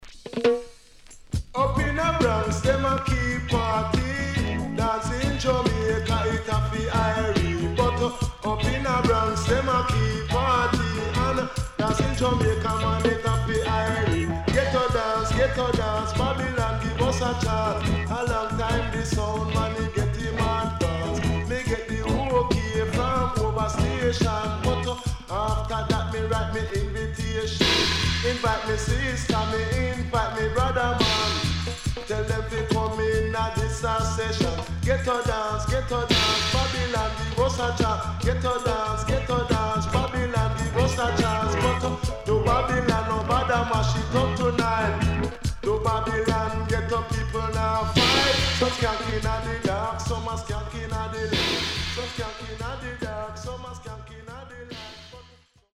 HOME > REISSUE USED [DANCEHALL]
riddim
SIDE A:所々チリノイズがあり、少しプチノイズ入ります。